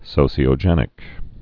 (sōsē-ō-jĕnĭk, -shē-)